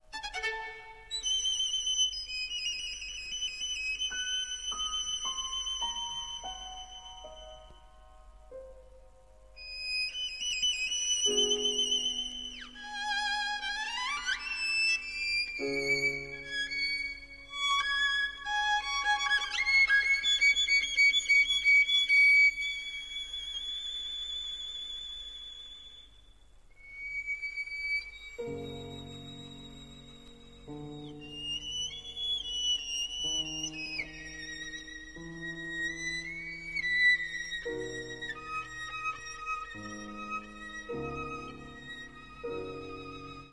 violin
piano